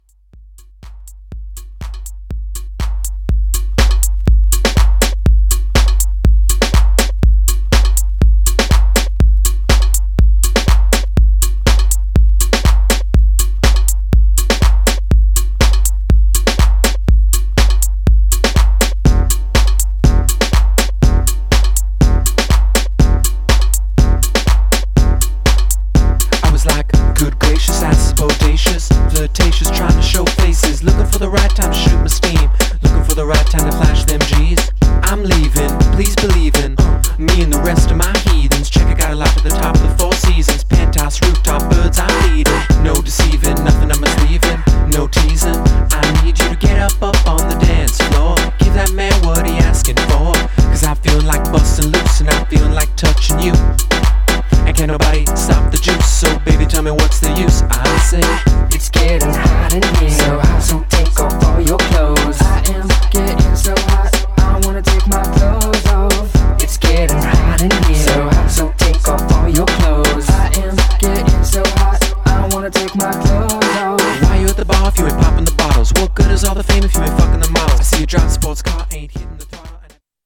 Styl: House, Breaks/Breakbeat, Minimal